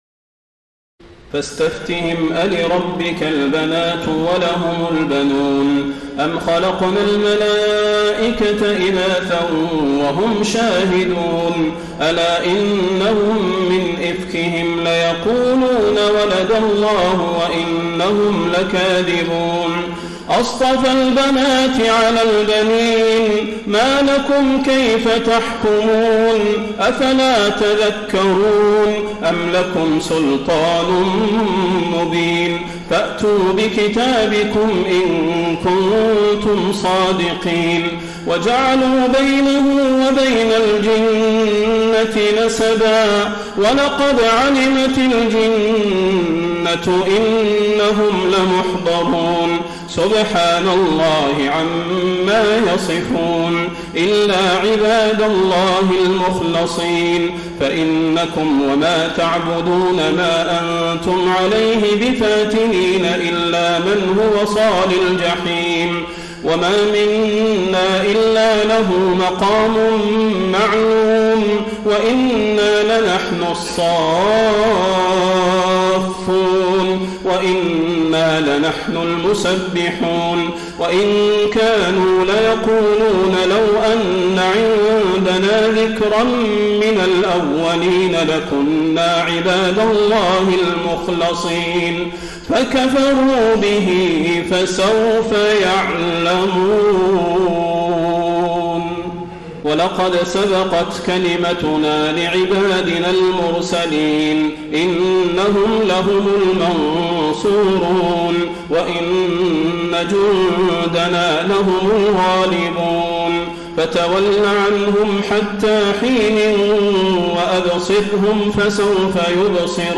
تراويح ليلة 22 رمضان 1433هـ من سور الصافات (149-182) وص و الزمر (1-31) Taraweeh 22 st night Ramadan 1433H from Surah As-Saaffaat and Saad and Az-Zumar > تراويح الحرم النبوي عام 1433 🕌 > التراويح - تلاوات الحرمين